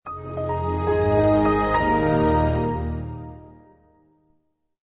Категория: Разные звуки